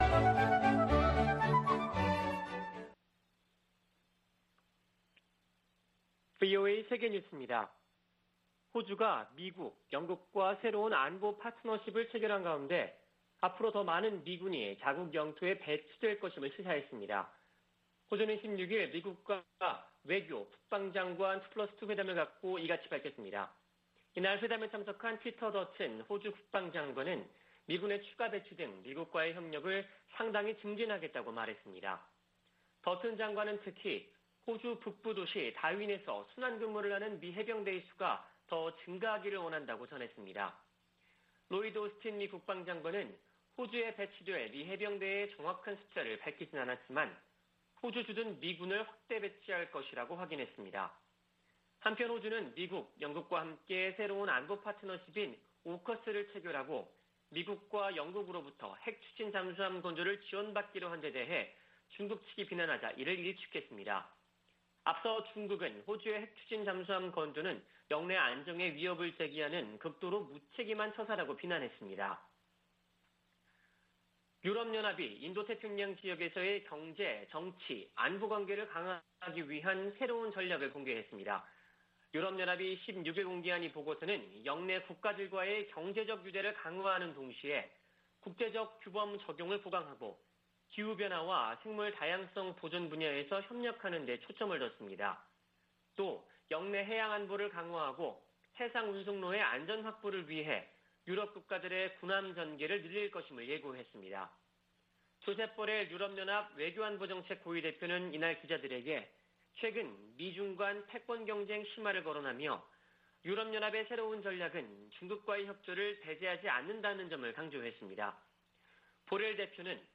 VOA 한국어 아침 뉴스 프로그램 '워싱턴 뉴스 광장' 2021년 9월 18일 방송입니다. 북한이 영변 우라늄 농축 시설을 확장하는 정황이 담긴 위성사진이 공개됐습니다. 76차 유엔총회가 14일 개막된 가운데 조 바이든 미국 대통령 등 주요 정상들이 어떤 대북 메시지를 내놓을지 주목됩니다. 유럽연합(EU)은 올해도 북한 인권 규탄 결의안을 유엔총회 제3위원회에 제출할 것으로 알려졌습니다.